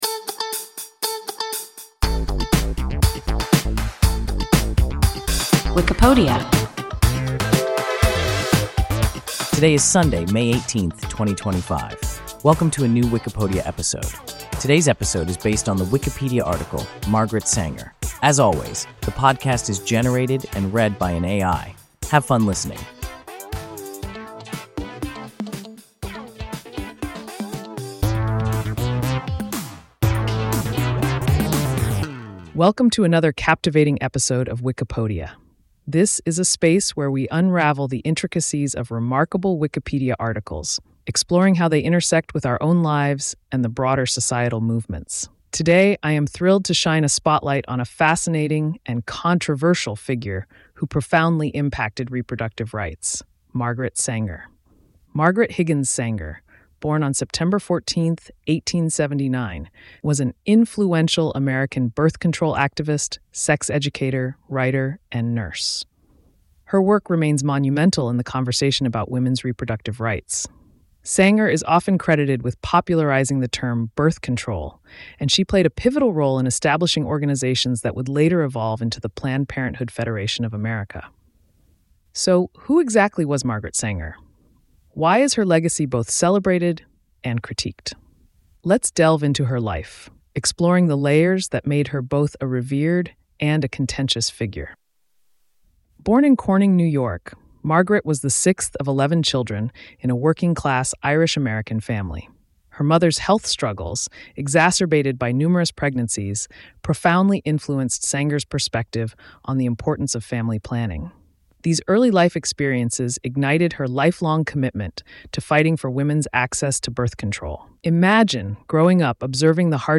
Margaret Sanger – WIKIPODIA – ein KI Podcast